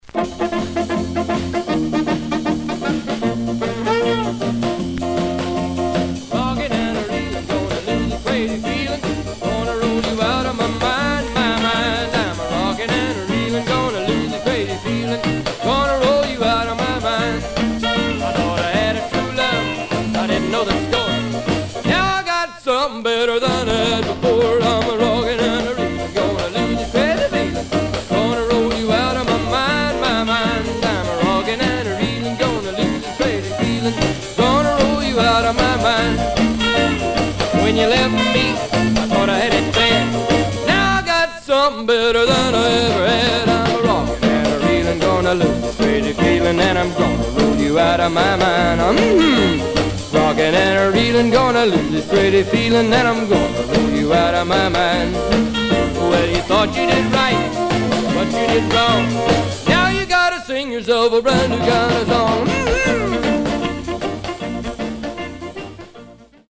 Surface noise/wear
Mono
Rockabilly